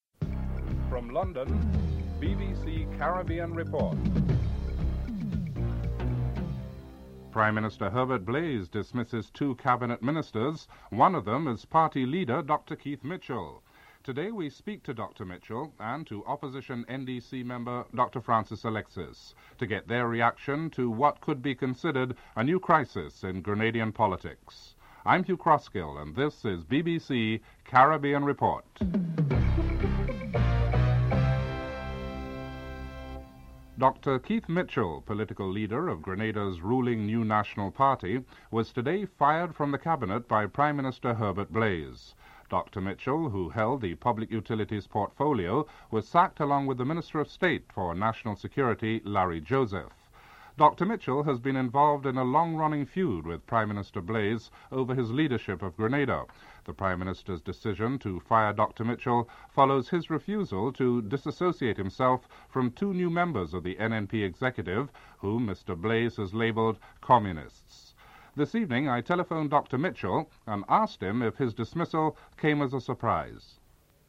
1. Headlines (00:00-00:35)
3. Financial News (08:21-10:03)